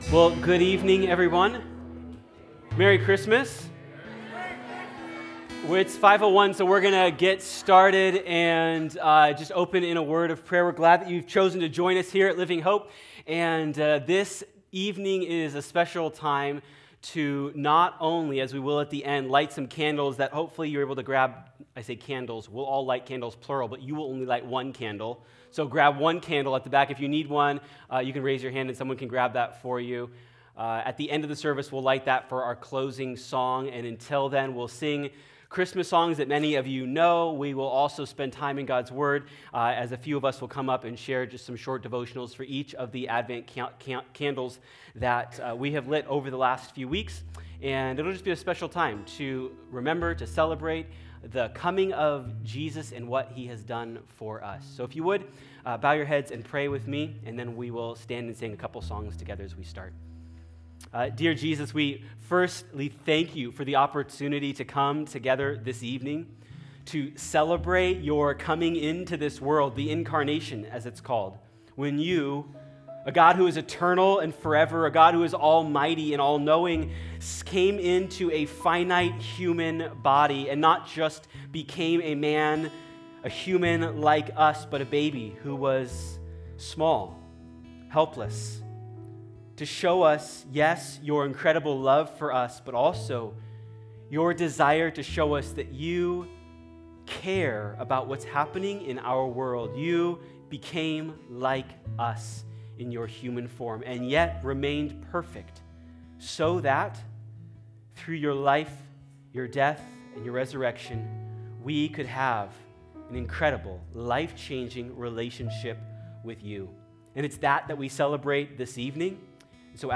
2024 Christmas Eve Service